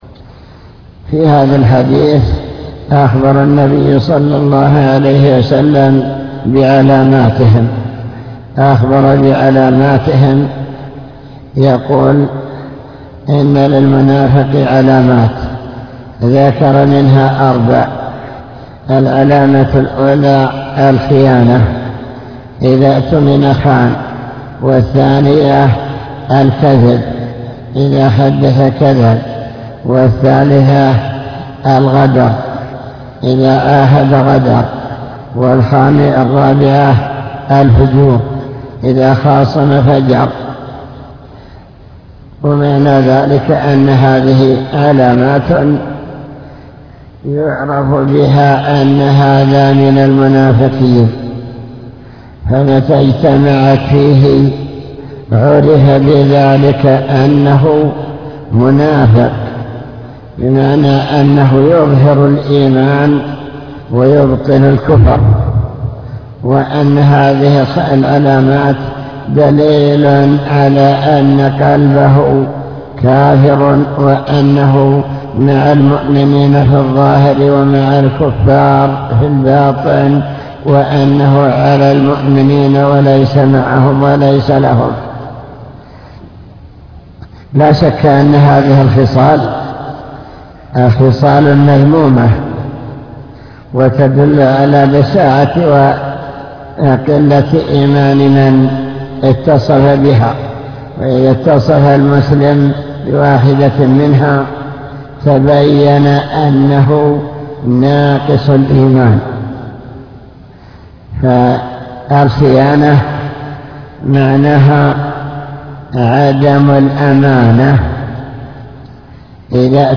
المكتبة الصوتية  تسجيلات - كتب  شرح كتاب بهجة قلوب الأبرار لابن السعدي شرح حديث أربع من كن فيه كان منافقا خالصًا صفات المنافقين